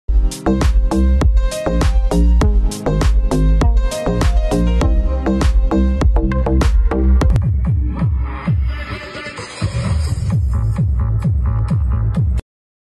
DIY A 5.25” Good Bass Sound Effects Free Download